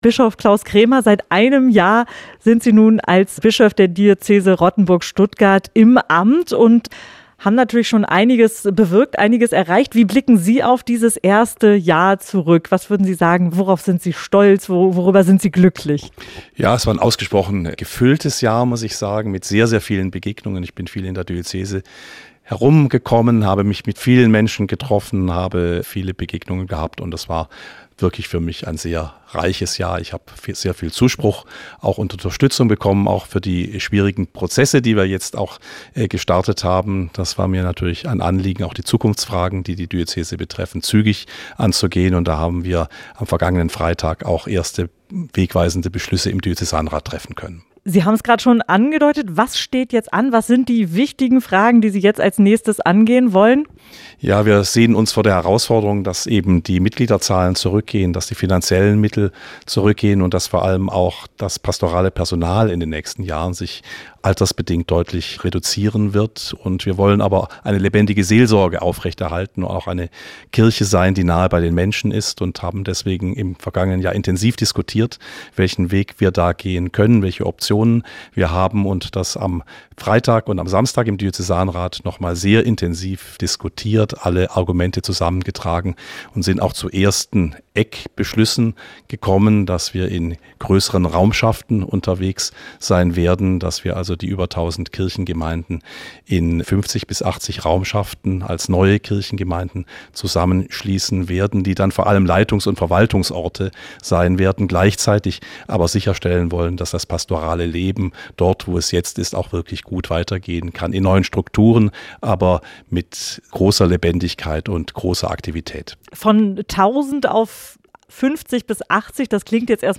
Gespräch im SWR Studio Tübingen
Bischof Klaus Krämer im SWR Studio Tübingen